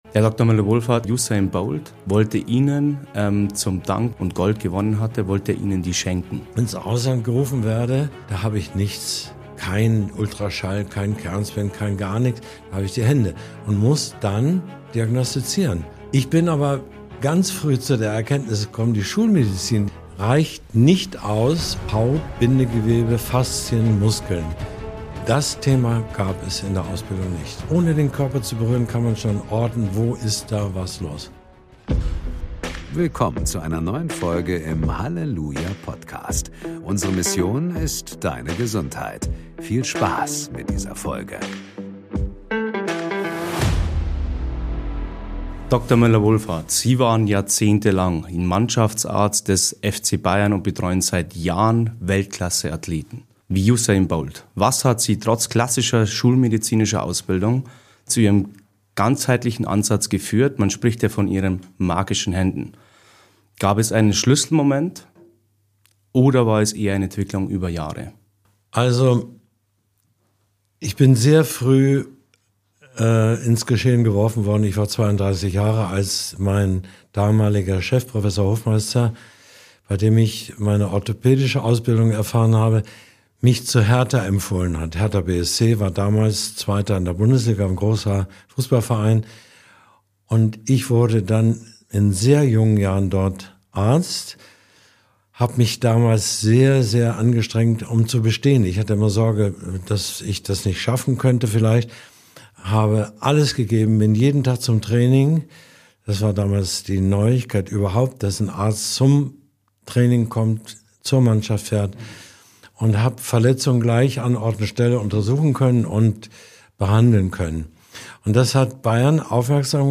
Dr. Müller-Wohlfahrt, jahrzehntelang Mannschaftsarzt des FC Bayern München und Begleiter von Legenden wie Usain Bolt, spricht über seine einzigartige Herangehensweise: Diagnose mit den Händen, Heilung ohne Cortison, Regeneration durch Wärme, Bewegung und Schlaf. Er erzählt, wie er Usain Bolt bei Olympia rettete, warum Schulmedizin nicht ausreicht, um Muskelverletzungen wirklich zu verstehen und wieso jeder Mensch seine Selbstheilung aktivieren kann, wenn er lernt, zu spüren statt zu betäuben.